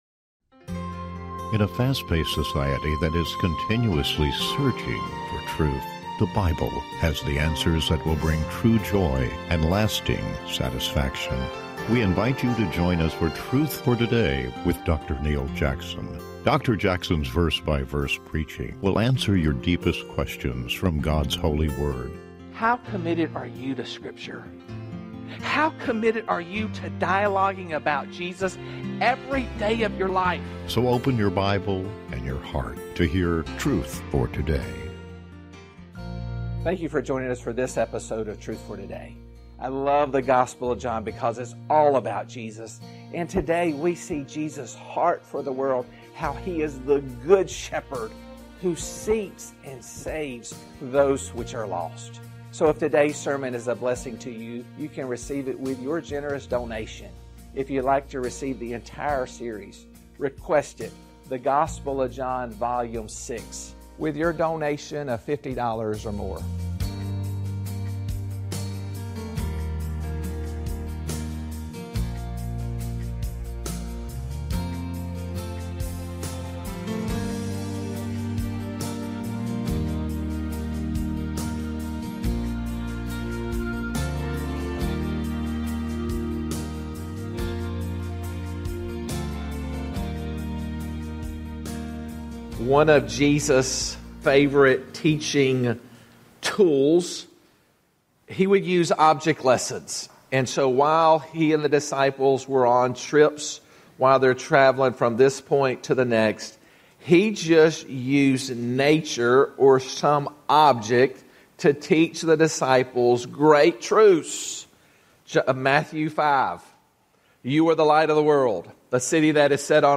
Talk Show Episode, Audio Podcast, Truth For Today and Lessons From A Grapevine on , show guests , about Lessons From A Grapevine, categorized as Health & Lifestyle,History,Love & Relationships,Philosophy,Psychology,Christianity,Inspirational,Motivational,Society and Culture